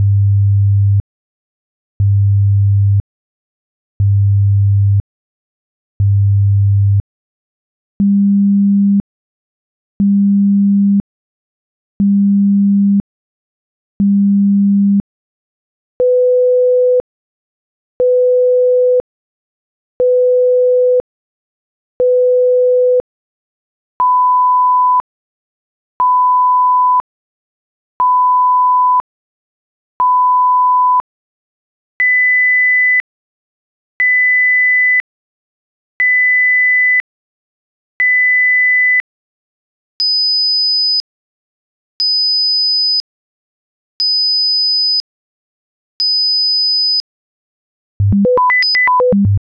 Atlas - STest1-Pitch-Right-100,200,500,1000,2000,5000.wav